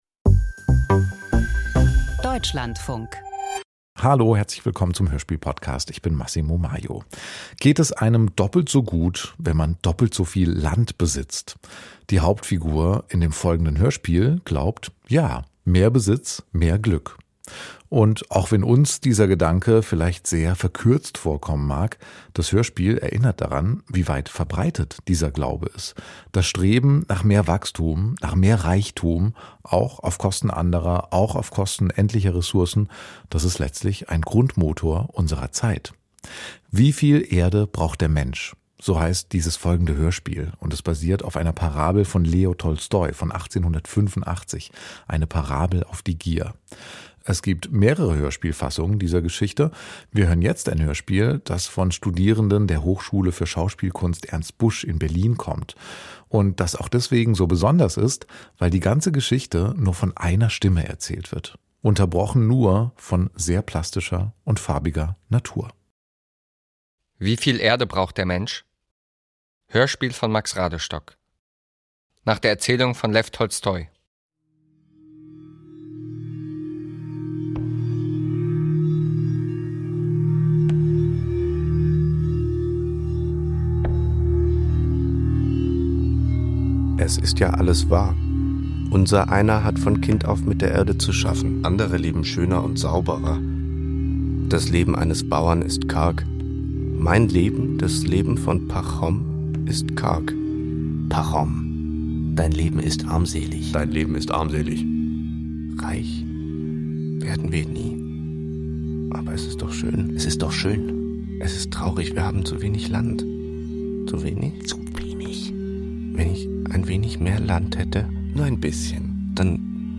Hörspiel nach der Erzählung von Leo Tolstoi - Wieviel Erde braucht der Mensch?